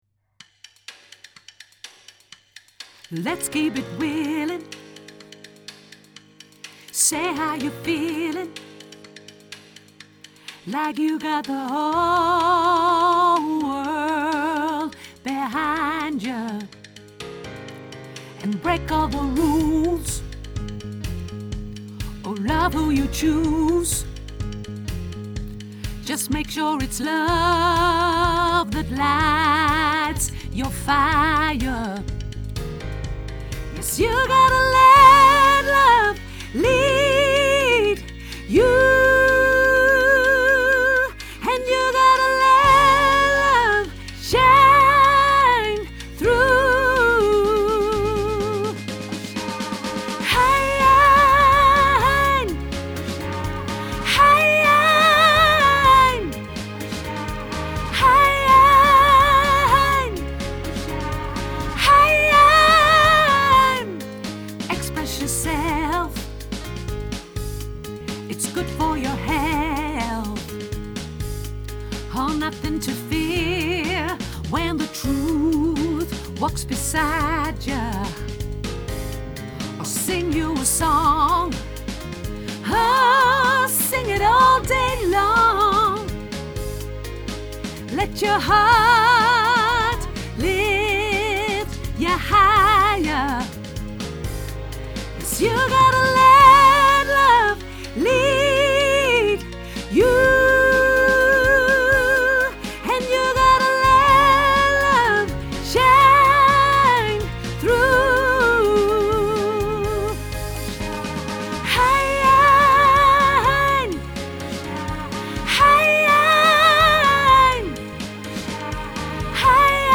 sopraan hoog